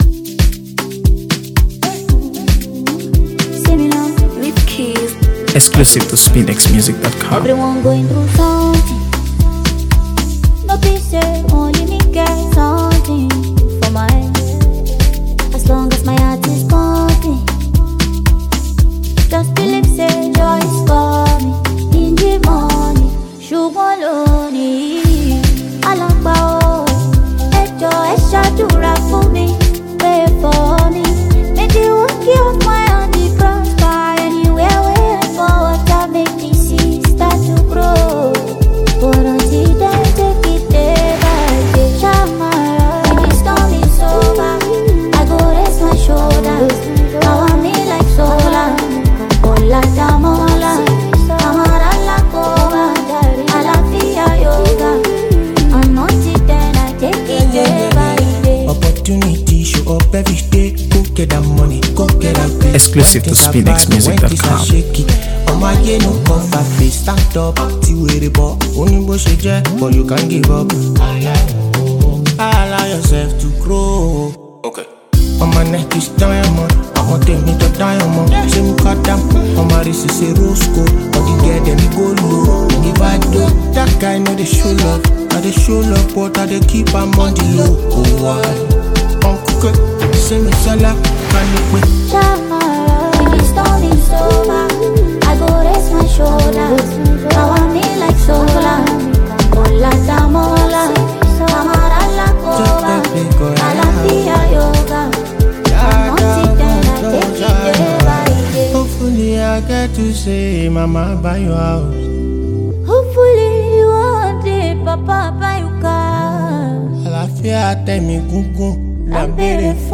AfroBeats | AfroBeats songs
a soulful new track